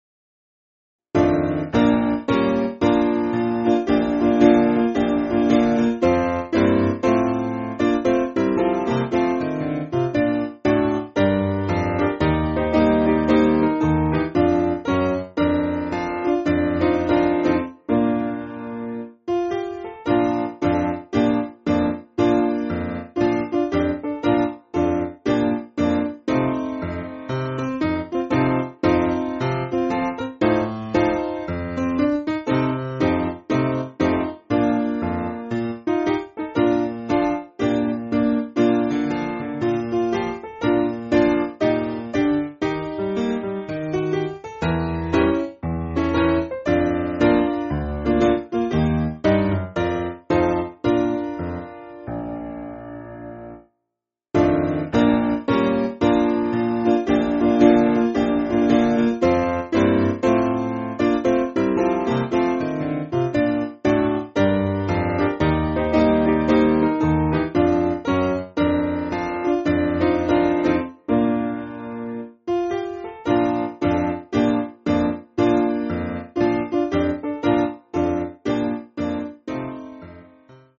Simple Piano
(CM)   3/Bb
Slower